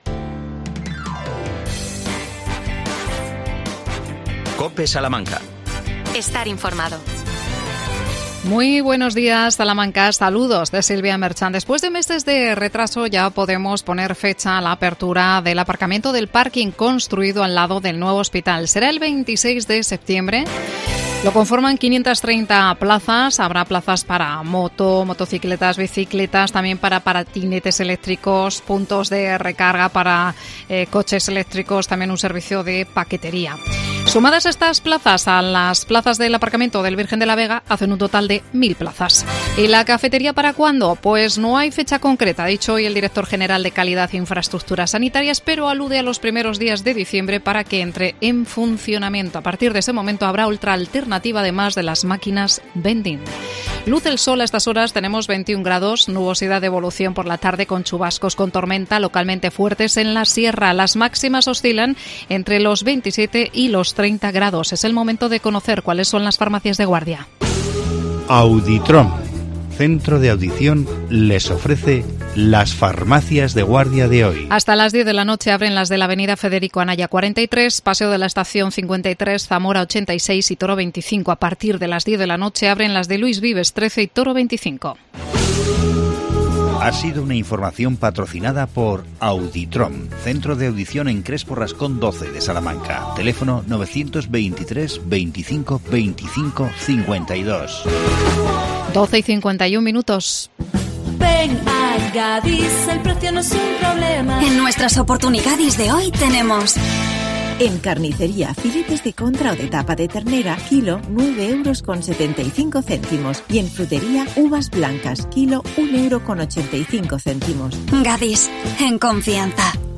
AUDIO: Entrevistamos al concejal de Juventud Ángel Fernández Silva para hablar del Programa Formativo Joven 2022